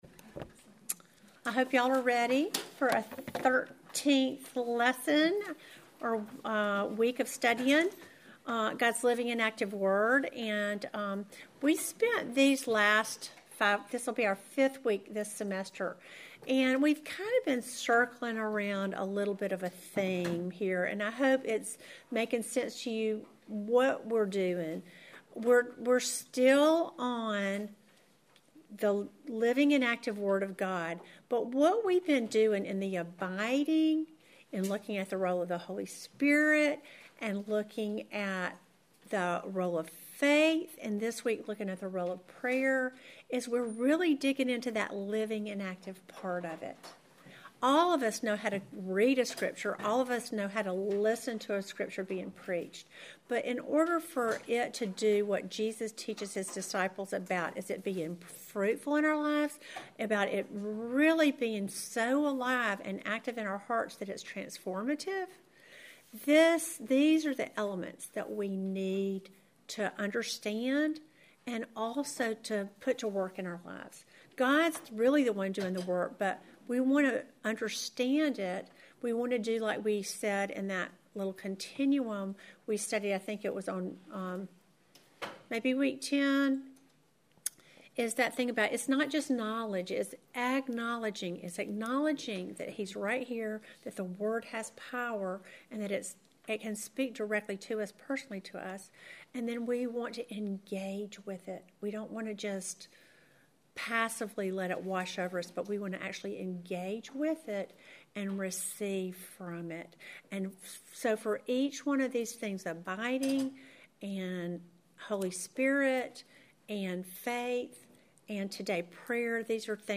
Welcome to the thirteenth lesson in our series GOD’S LIVING AND ACTIVE WORD!